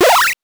8 bits Elements
powerup_17.wav